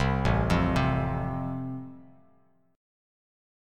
Am#5 chord